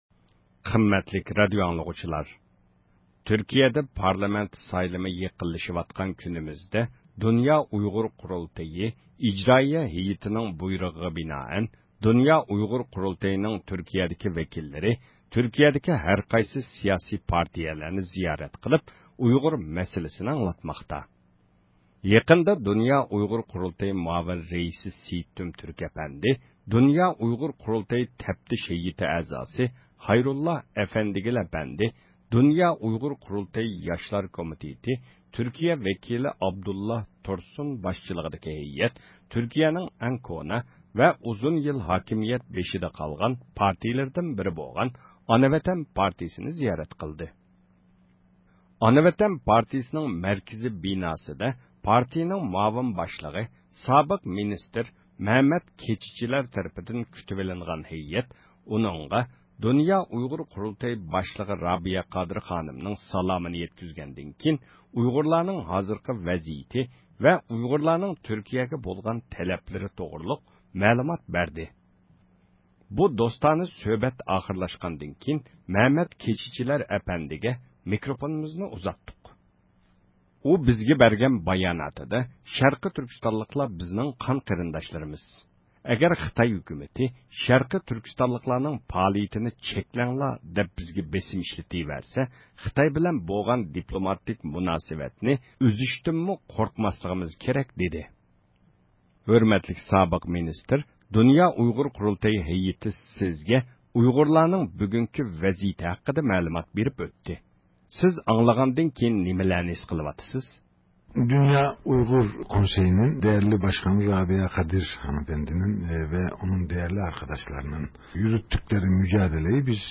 بۇ دوستانە سۆھبەت ئاخىرلاشقاندىن كېيىن مەمەت كېچىجىلەر ئەپەندىگە مىكروفونىمىزنى ئۇزاتتۇق. ئۇ بىزگە بەرگەن باياناتىدا شەرقىي تۈركىستانلىقلار بىزنىڭ قان قېرىنداشلىرىمىز، ئەگەر خىتاي ھۆكۈمىتى شەرقىي تۈركىستانلىقلارنىڭ پائالىيىتىنى چەكلەڭلار دەپ بىزگە بېسىم ئىشلىتىۋەرسە، خىتاي بىلەن بولغان دىپلوماتىك مۇناسىۋەتنى ئۈزۈشتىنمۇ قورقماسلىقىمىز كېرەك، دىدى.
مۇخبىرىمىزنىڭ سۇئاللىرى